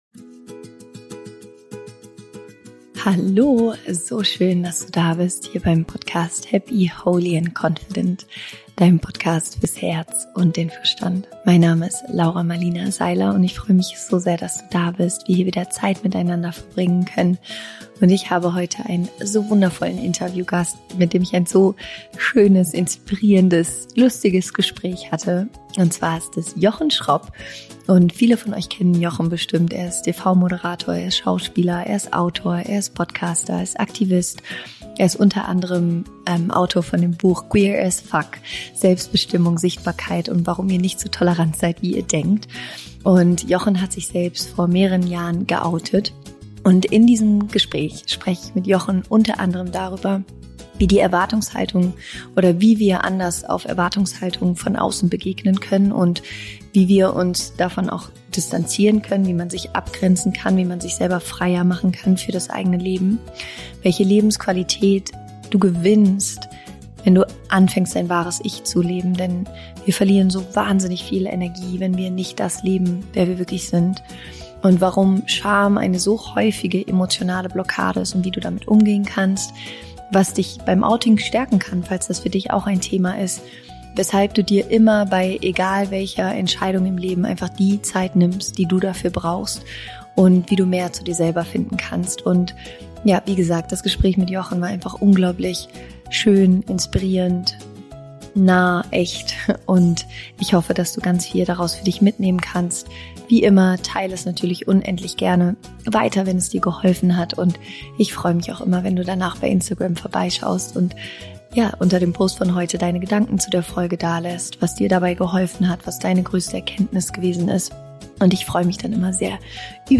Genau darüber spreche ich mit meinem heutigen Podcastgast – dem großartigen Schauspieler und Fernsehmoderator Jochen Schropp.